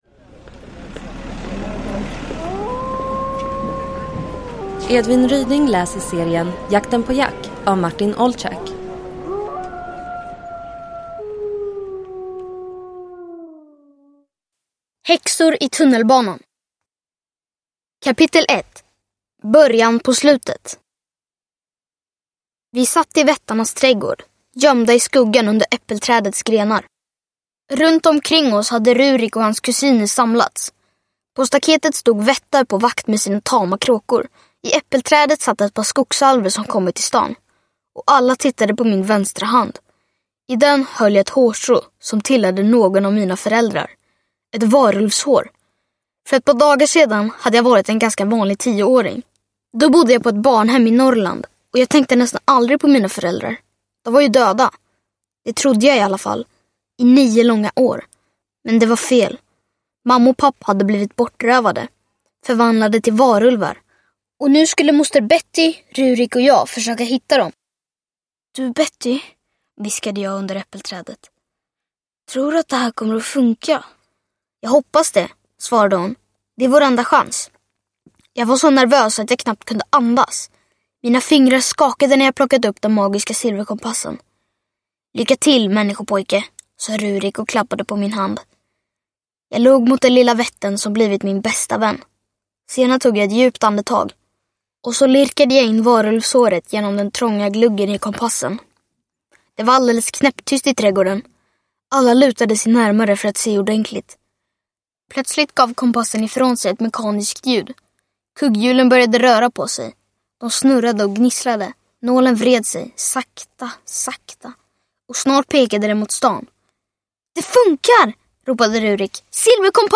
Häxor i tunnelbanan – Ljudbok
Uppläsare: Edvin Ryding